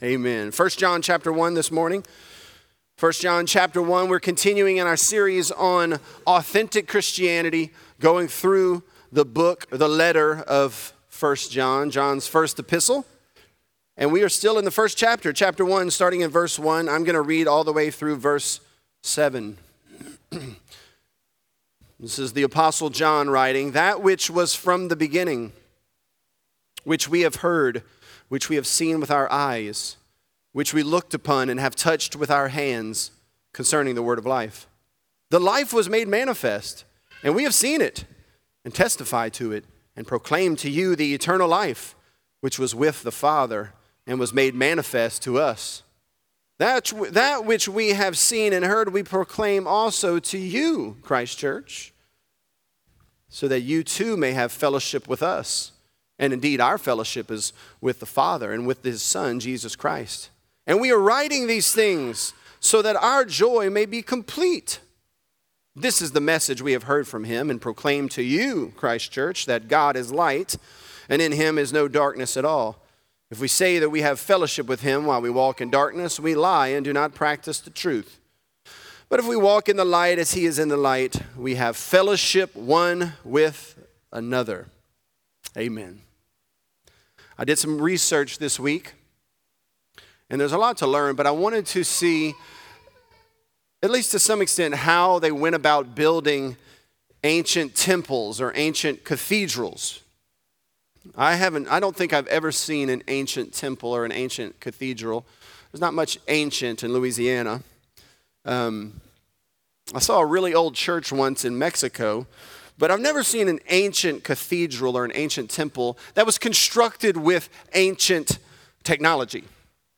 This is a part of our sermon series, "Authentic."Christ Church Lafayette